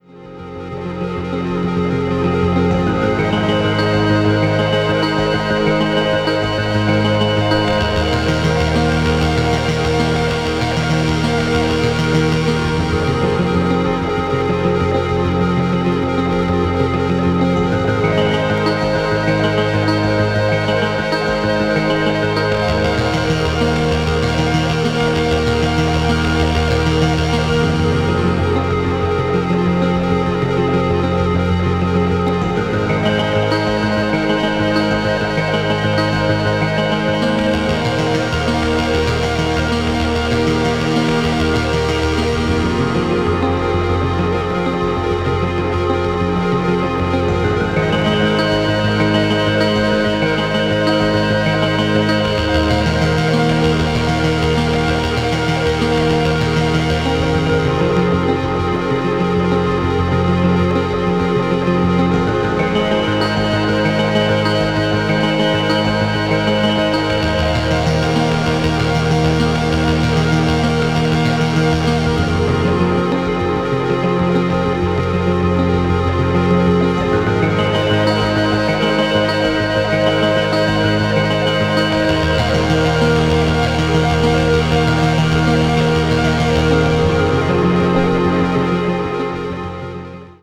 features the beautiful arpeggios of the Polymoog